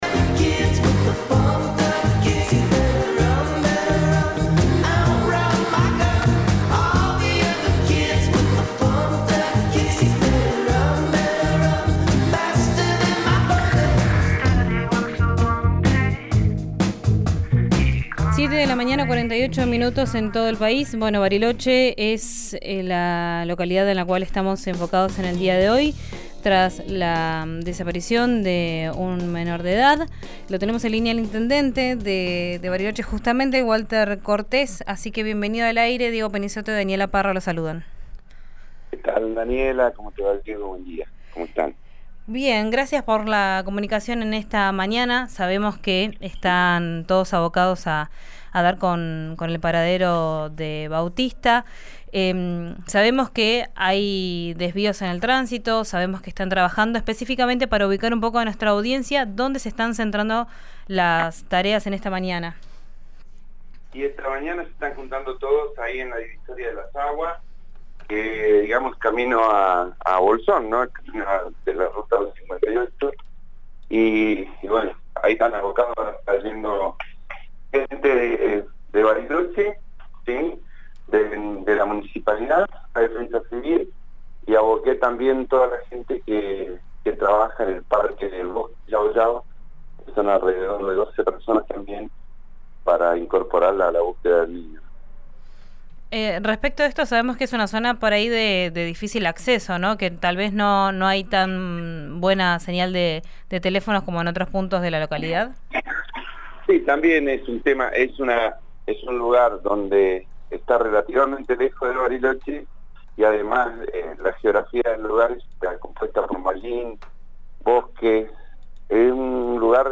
Escuchá a Walter Cortés, intendente de Bariloche, en RÍO NEGRO RADIO: